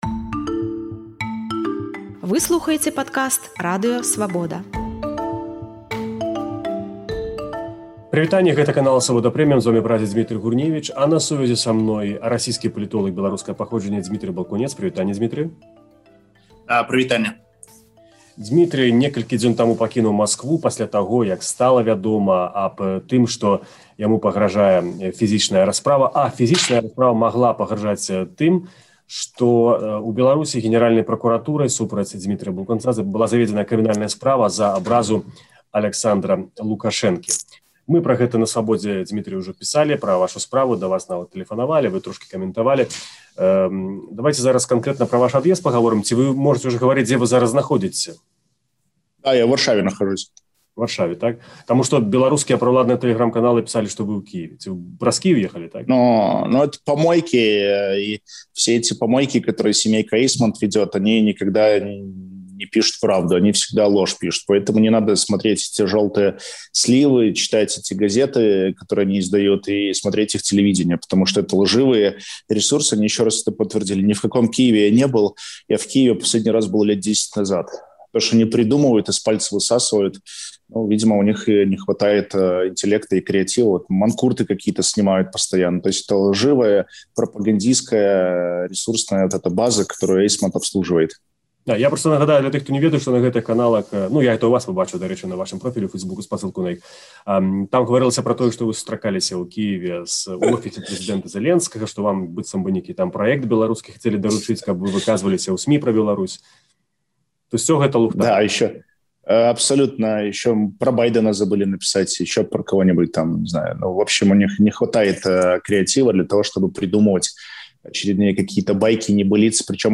26 сакавіка Генэральная пракуратура Беларусі завяла супраць расейскага палітоляга беларускага паходжаньня крымінальную справу за абразу Лукашэнкі. Днямі ён пакінуў Расею ў сувязі з рызыкай фізычнай расправы. У інтэрвію «Свабодзе»